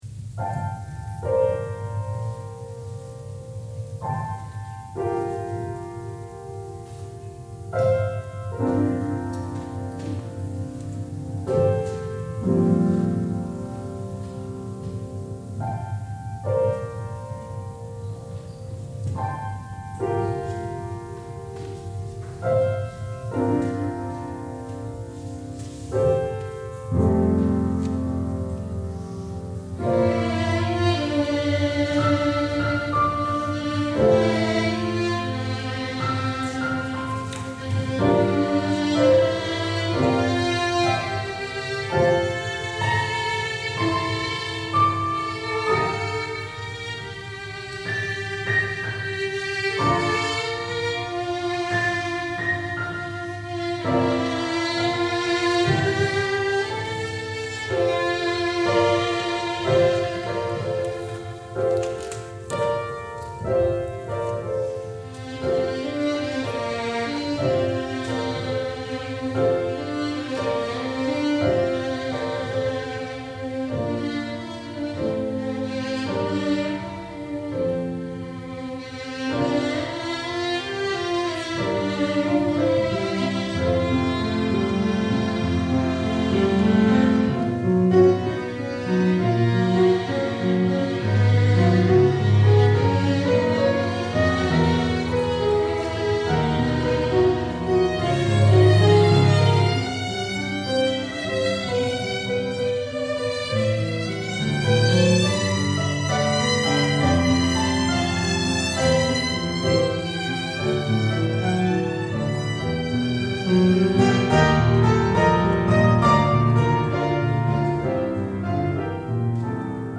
Lyric and virtuosic variations on the original melody